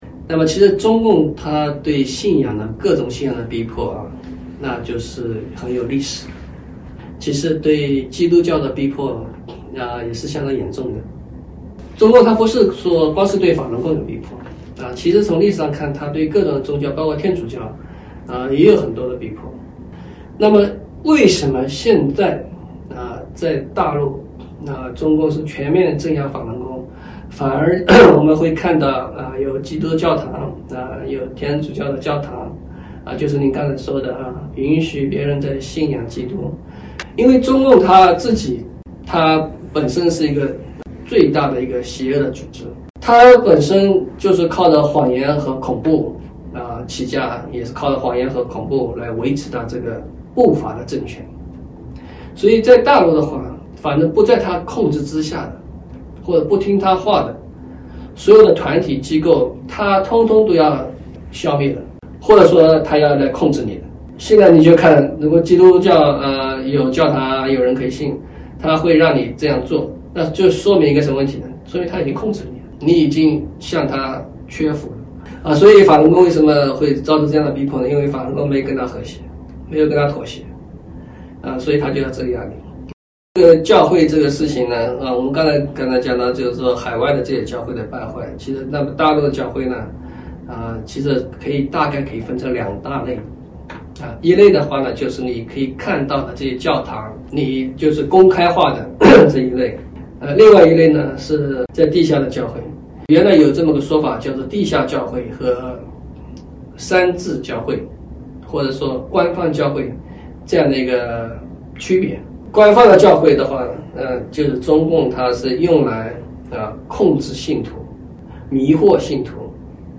7月14日（星期三）纽约整点新闻
听众朋友您好！今天是7月14号，星期三，欢迎收听WQEQFM105.5法拉盛之声广播电台整点新闻。